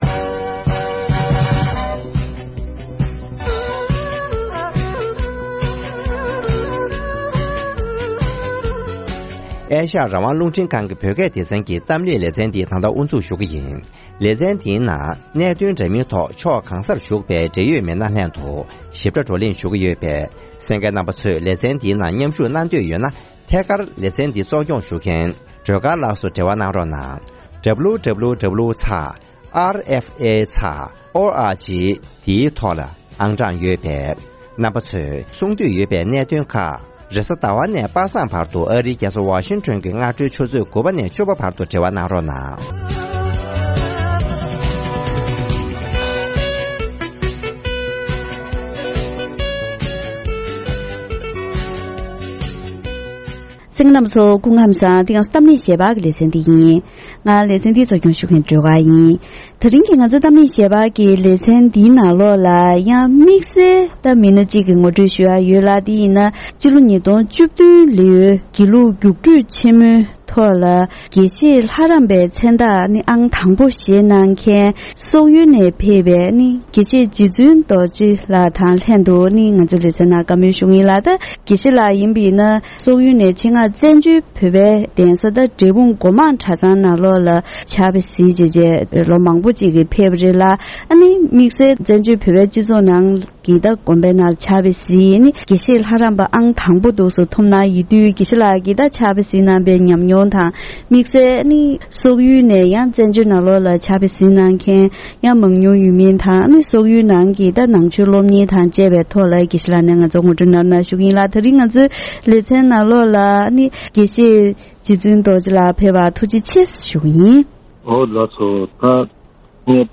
ཐེངས་འདིའི་གཏམ་གླེང་ལེ་ཚན་ནང་།